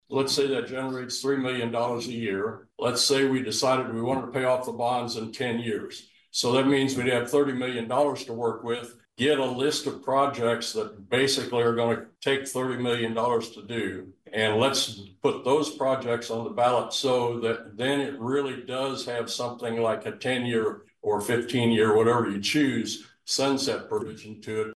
A Pottawatomie County Planning Board member also spoke during public comment, noting he is supportive of the county exploring another sales tax question on the November ballot to address infrastructure needs.